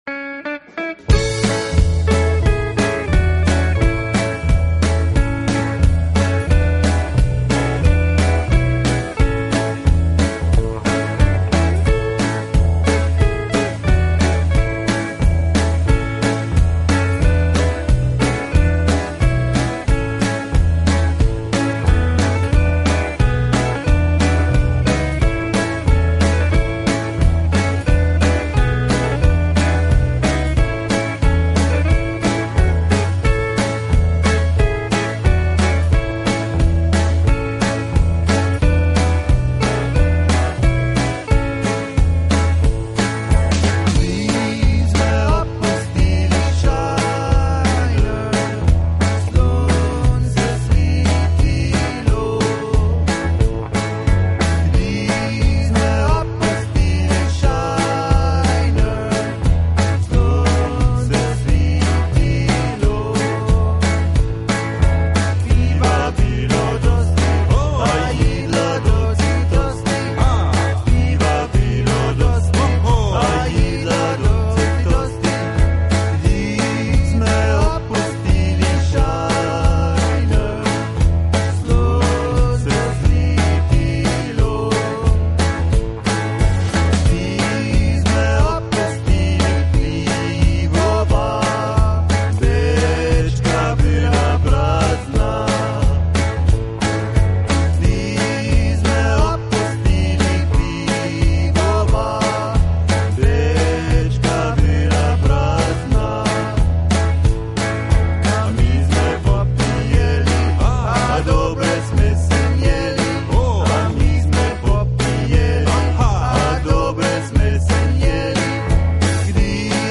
Polka Medley 5.
Commentary 8.